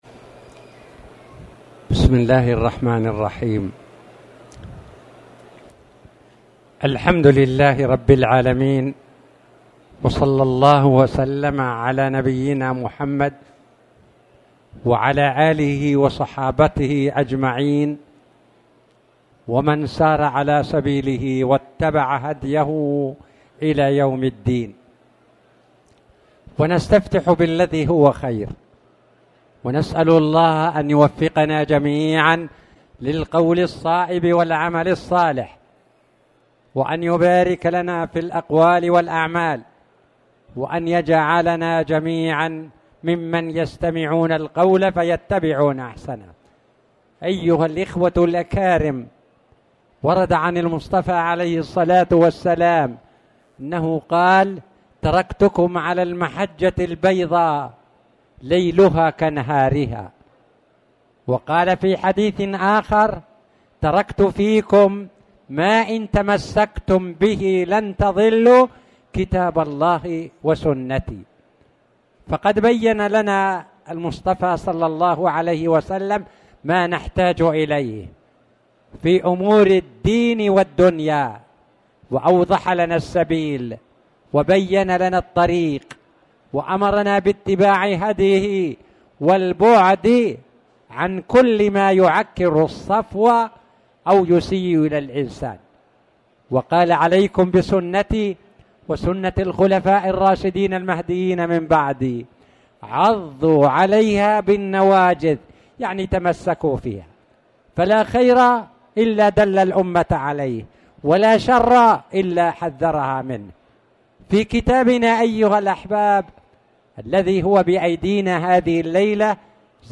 تاريخ النشر ١٩ شوال ١٤٣٨ هـ المكان: المسجد الحرام الشيخ